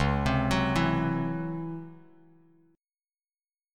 C#mM11 chord